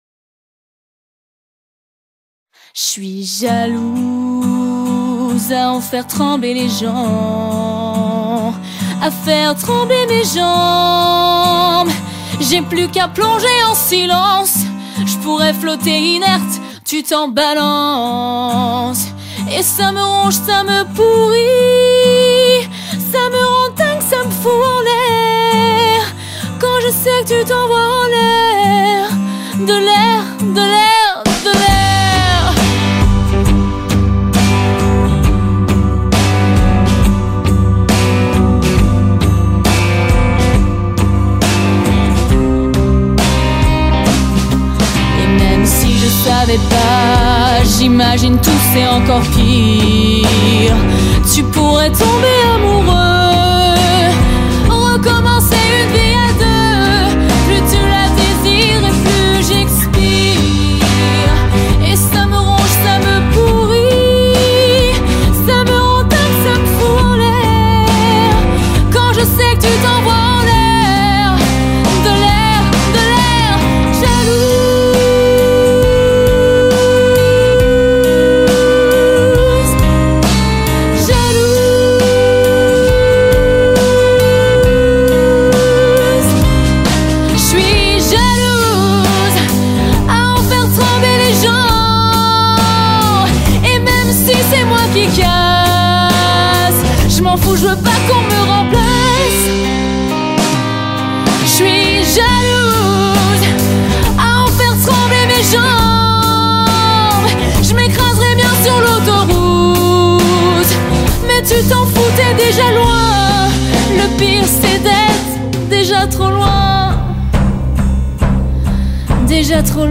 cover
Chanteuse